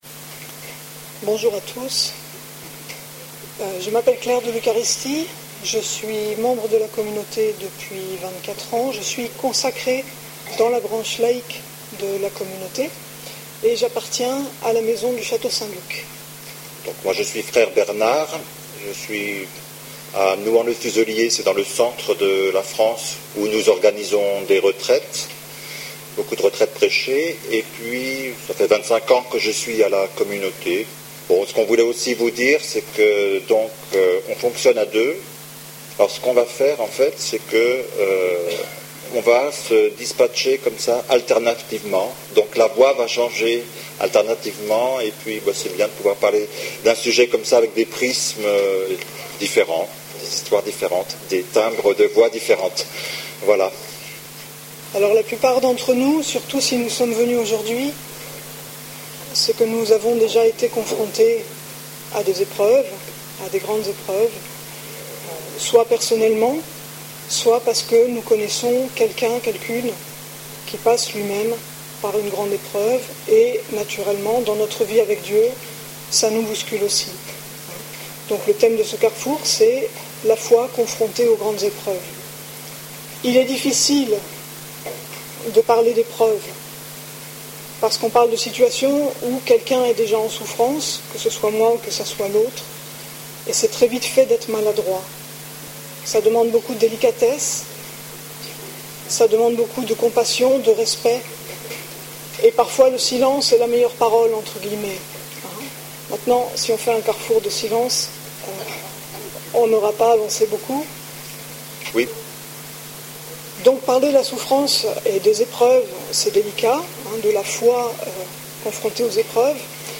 (Session B�atitudes Lourdes)